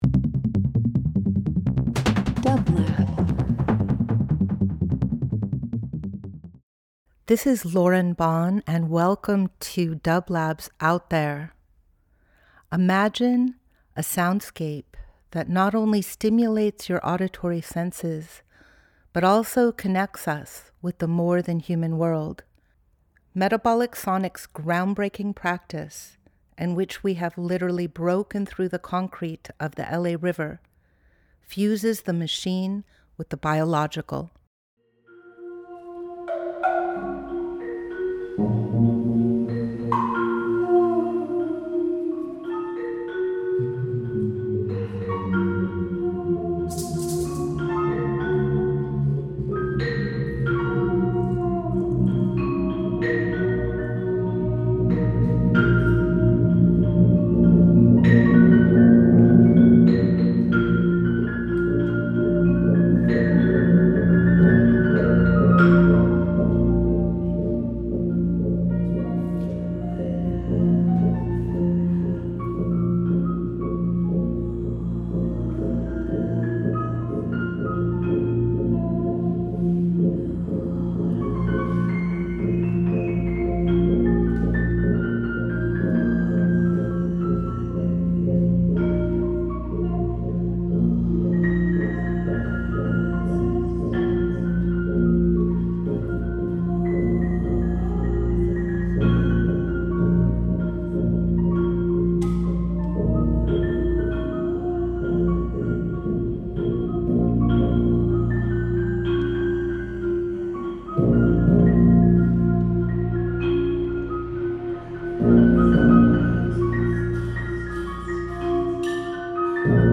Each week we present field recordings that will transport you through the power of sound. Metabolic Sonics specializes in exploring the captivating sound waves and vibrations of the web of life.
Metabolic Sonics Metabolic Studio Out There ~ a field recording program 12.18.25 Ambient Field Recording Voice Voyage with dublab into new worlds.
Today we are sharing excerpts from the second half of the Metabolic Sonics jamming an accompaniment to the REIMAGINE printmaking gathering at Metabolic Studio on December 1, 2025.